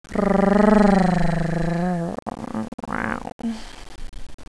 Sexy female purring
fempurr.wav